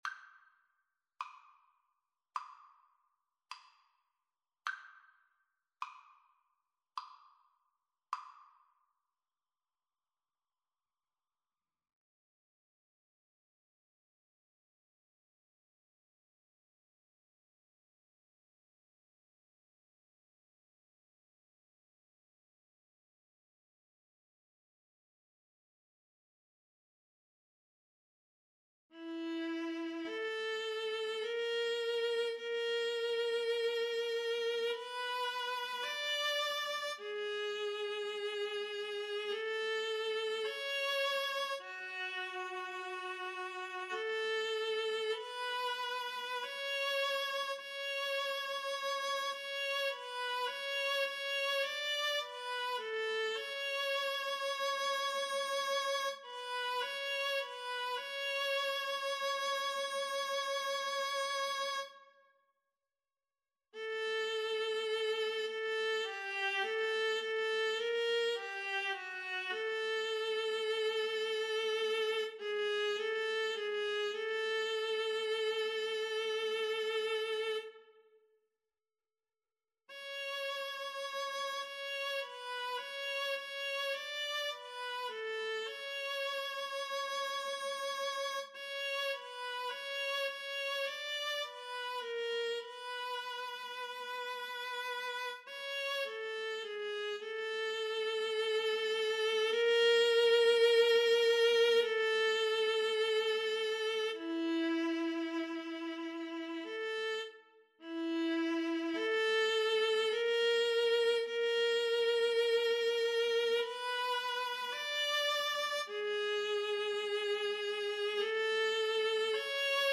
4/4 (View more 4/4 Music)
Adagio sostenuto ( = 52)
Viola Duet  (View more Intermediate Viola Duet Music)
Classical (View more Classical Viola Duet Music)